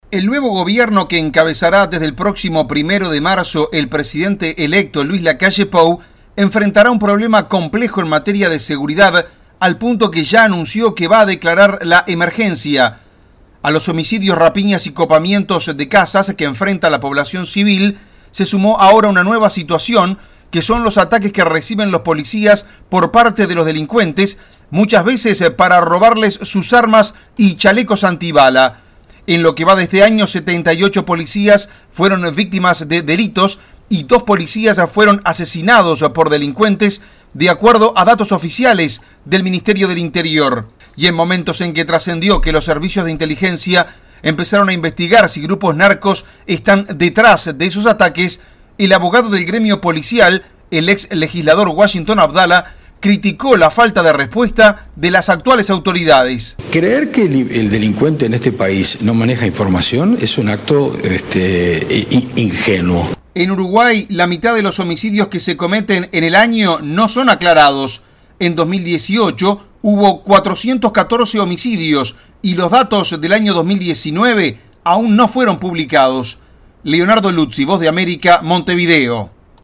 VOA: Informe de Uruguay